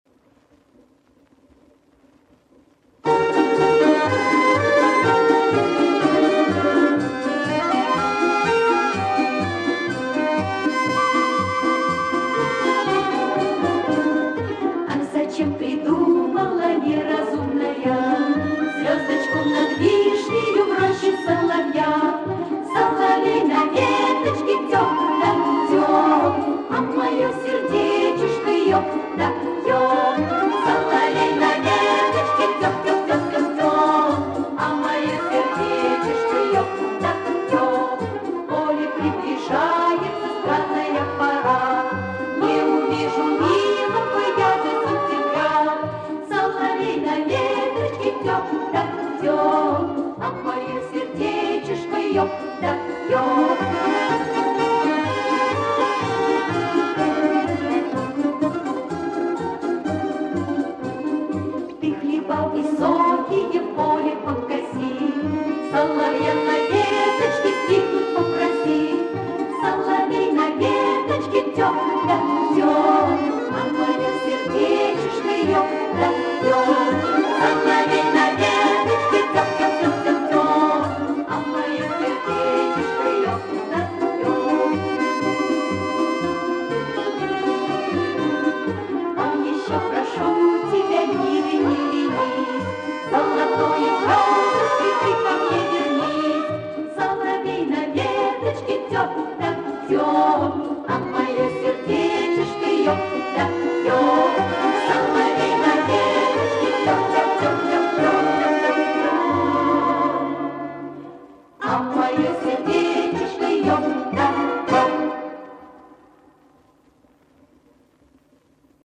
Спасибо, правда звук не очень